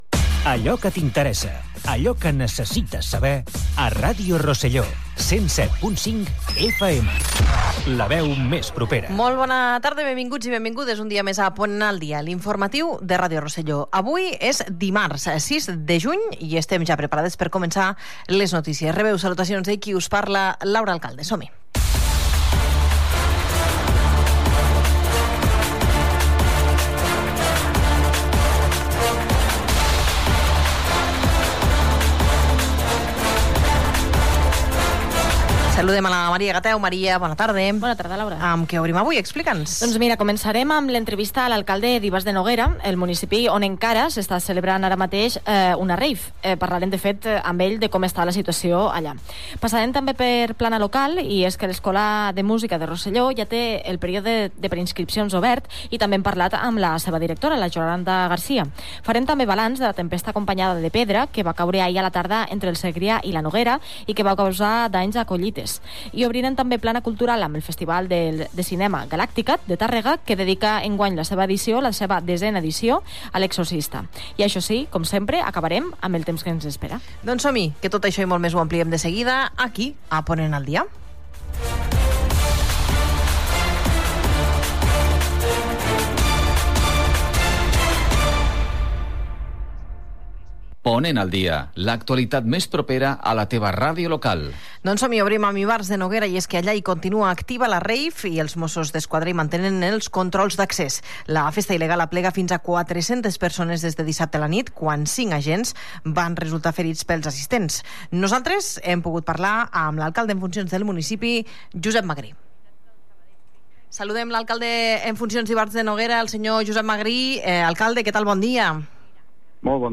Indicatiu de l'emissora, data, sumari informatiu, indicatiu de l'emissora, "rave" a Ivars de Noguera, entrevista a l'alcalde en funcions Josep Magrí.
Informatiu
FM